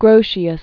(grōshē-əs, -shəs), Hugo Originally Huig de Groot. 1583-1645.